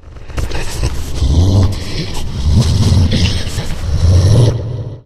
breath_1.ogg